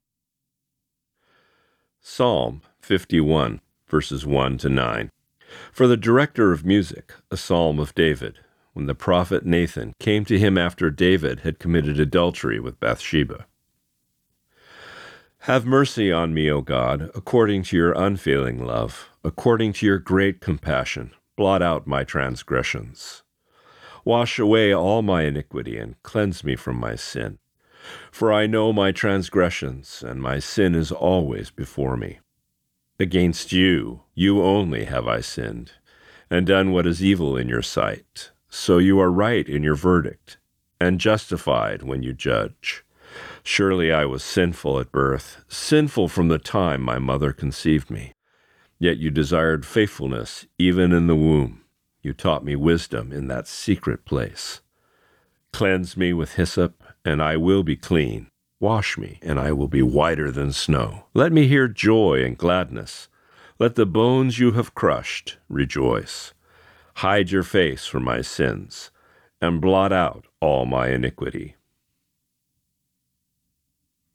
Reading: Psalm 51:1-9